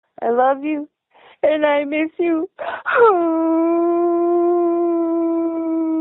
ninja-i-miss-youuuuuuuu_03dwsnf.mp3